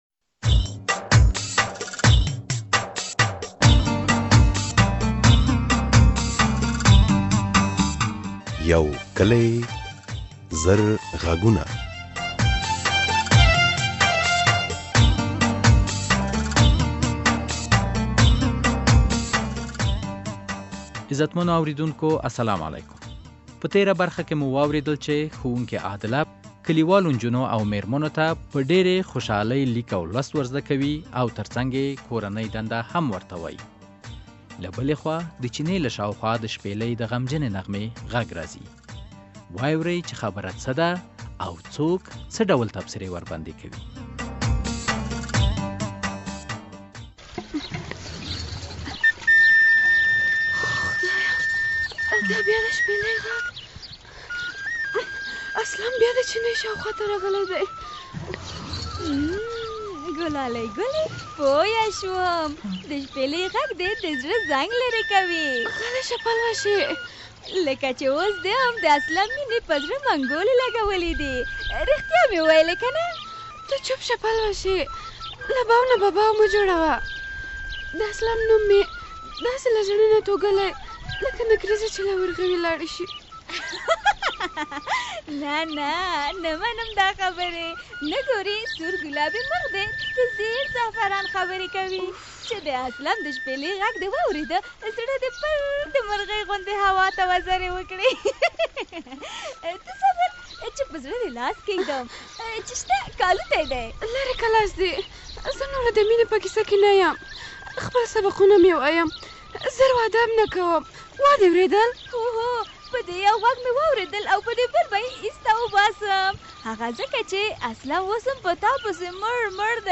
د یو کلي زر غږونو ډرامې ۱۹۸ برخه په کلي کې د ښوونکو رول ته اشاره شوې ده، و اوری چې د اسلم د شپېلۍ...